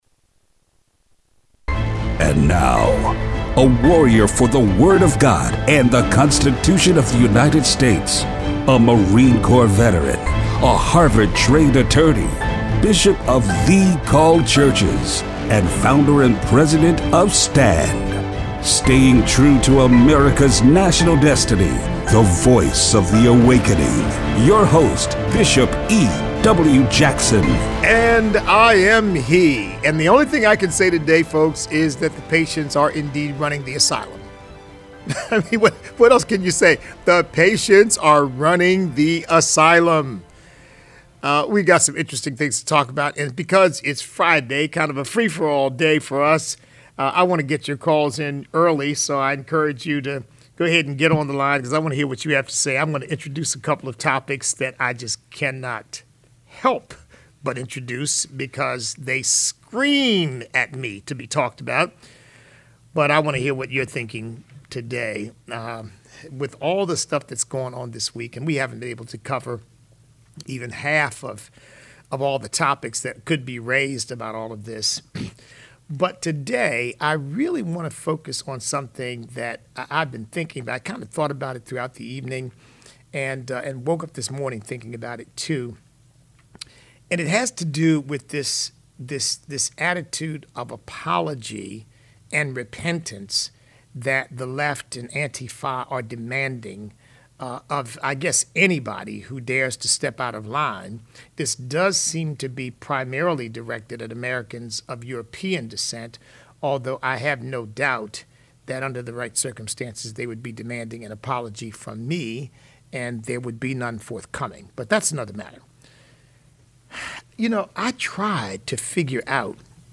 We take your calls this hour.